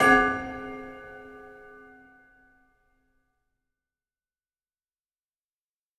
Celesta Hit 2
Category: Percussion Hits
Celesta-Hit-2.wav